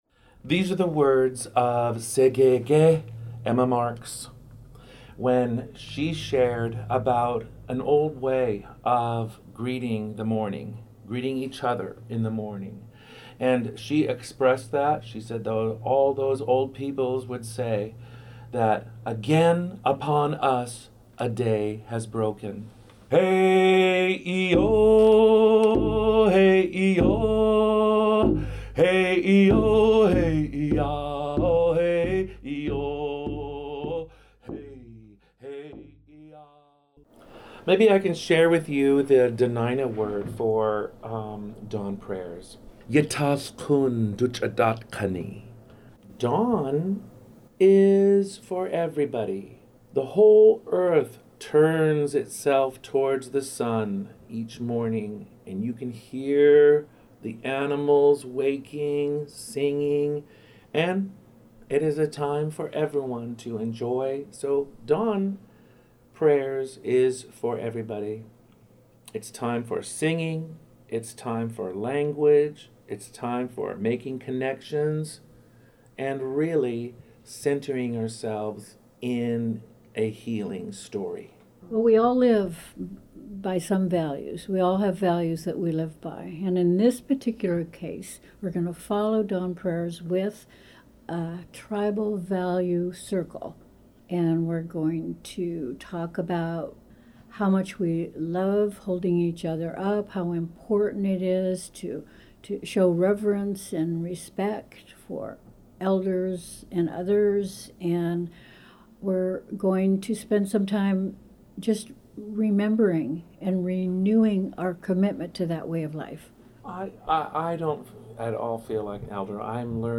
This is Tongass Voices, a series from KTOO sharing weekly perspectives from the homelands of the Áak’w Kwáan and beyond.